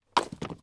landing-on-the-ground-4.wav